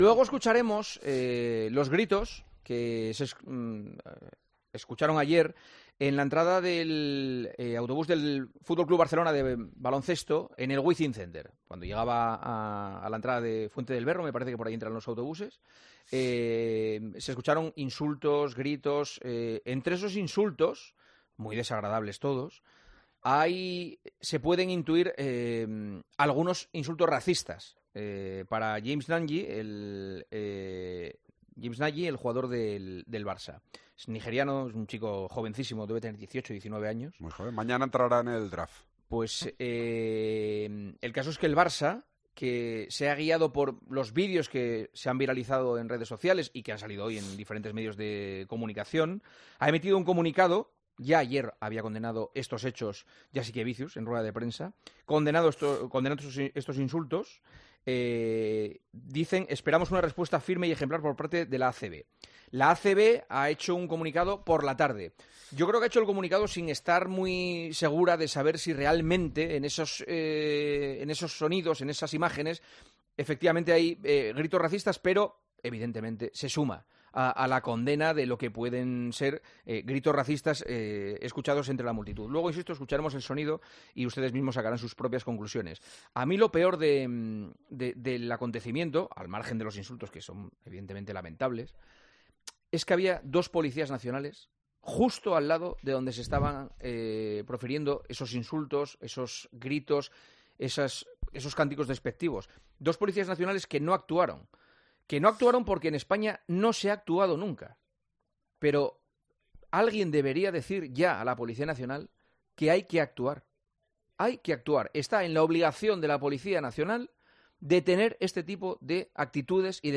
AUDIO: El director de El Partidazo de COPE da su opinión para frenar todas estas actitudes y erradicar la impunidad.